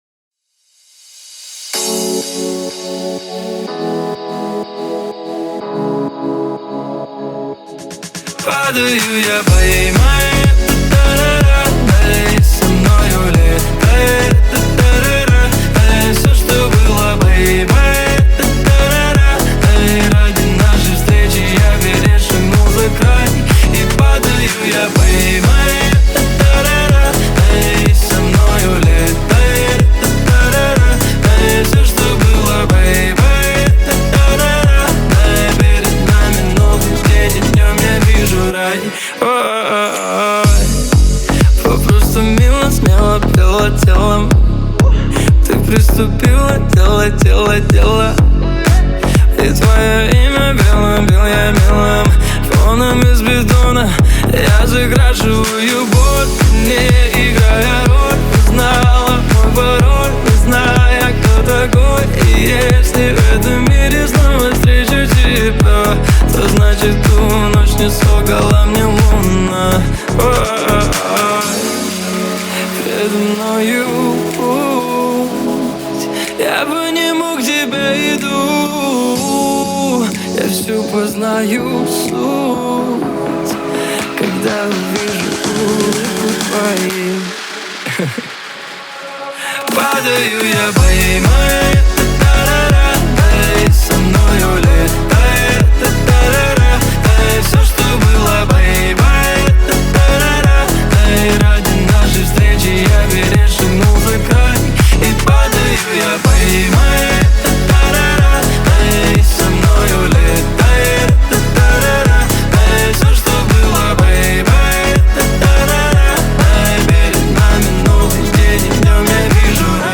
Эмоциональный вокал
а ритмичные биты добавляют драйва.